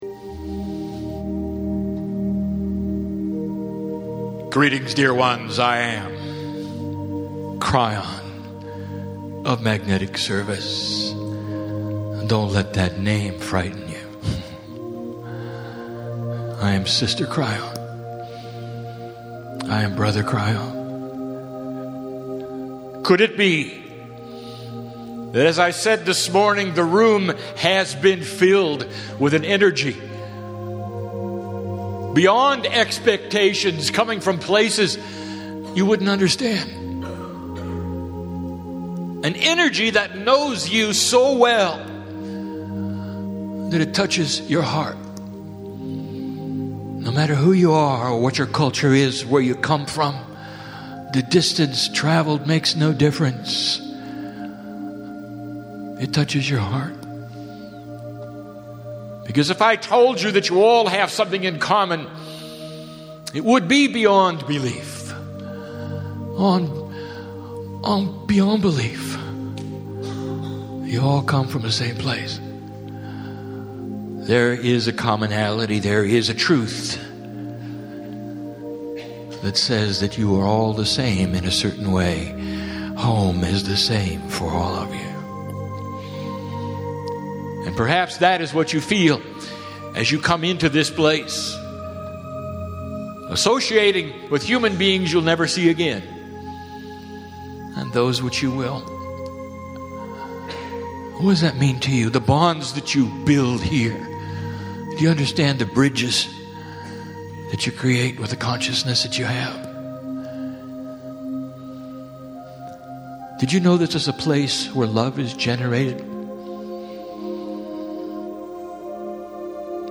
Mt. Shasta, California - June 16, 2007 KRYON SUMMER LIGHT CONFERENCE 58-minute channelling
Live music
INSTRUCTIONS: 43 megabytes 58 minutes High-quality Stereo - MP3 Filename: "ShastaSAT(F).mp3" PC - Right-click the left image to download the file.